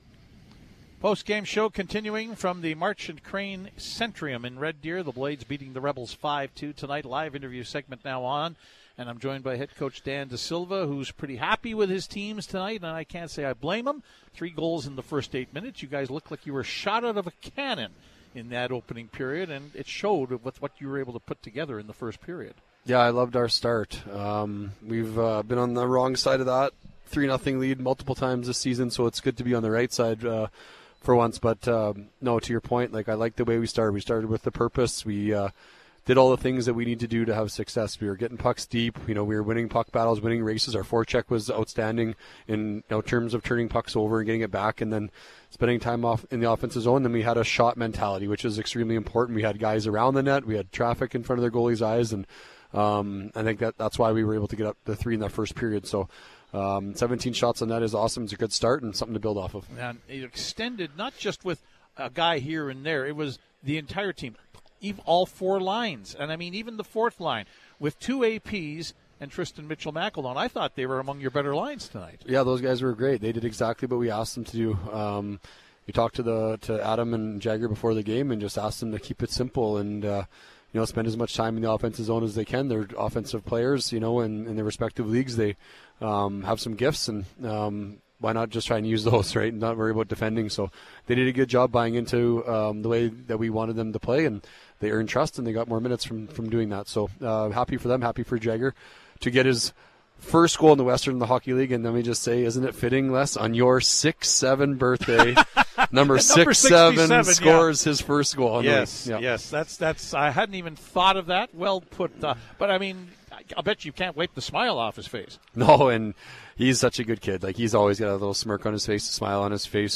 Post-Game Show interviews